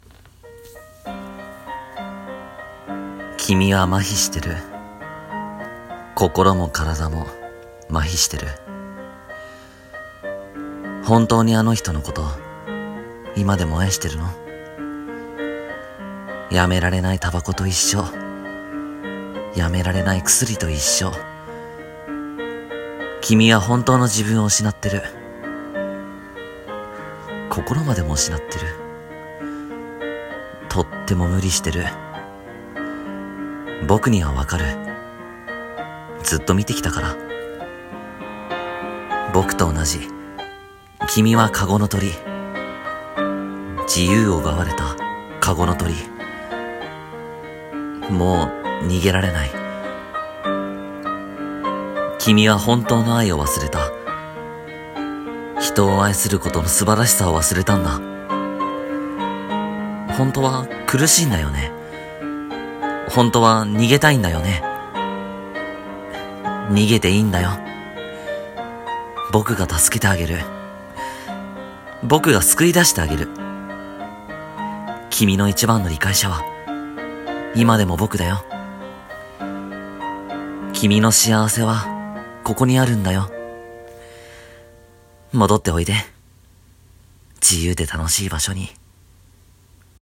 あなたの居場所【一人声劇】